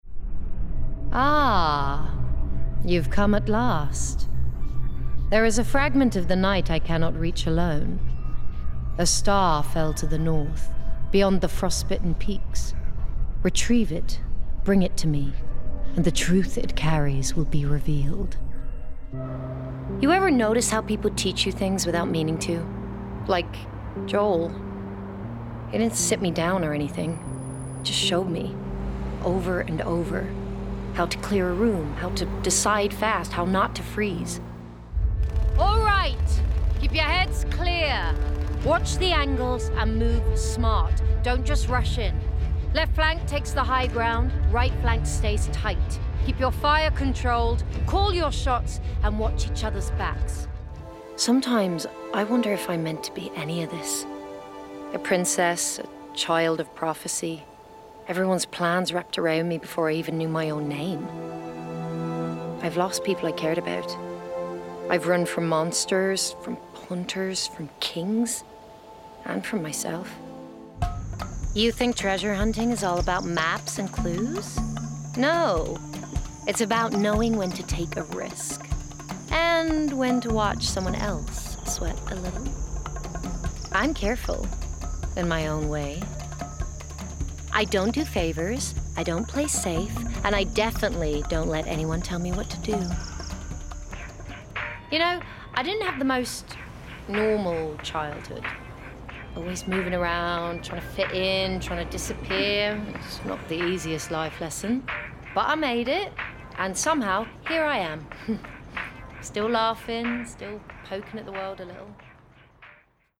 20/30's Neutral/RP,
Husky/Natural/Engaging
Gaming Showreel